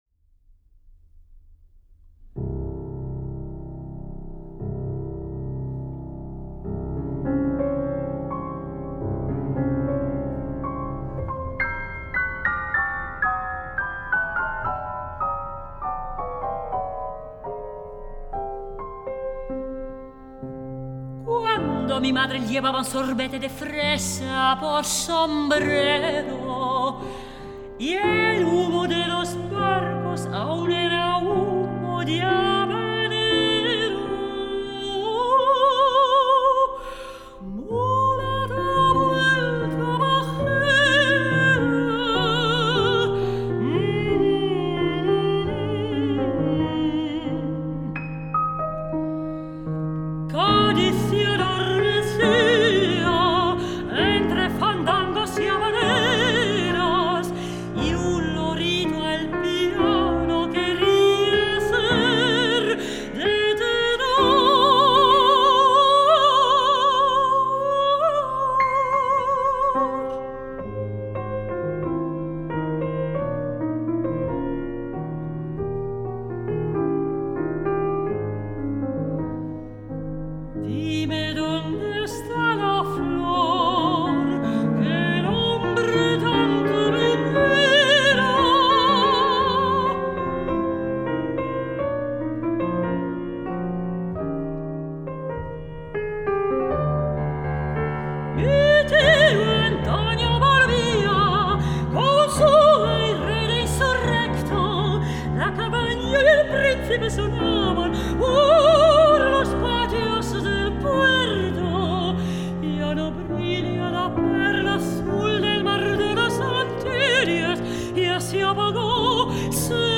Singing
Piano